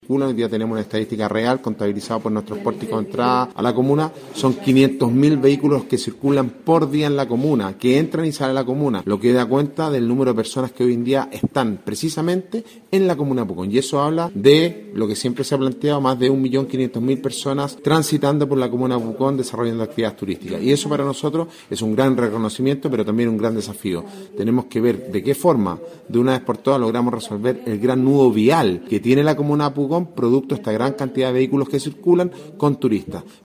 El alcalde de Pucón, Sebastián Álvarez, dijo que un total de 500 mil vehículos transitan diariamente por la comuna, lo que implica un gran desafío de gestión para el municipio.
cu-alcalde-de-pucon.mp3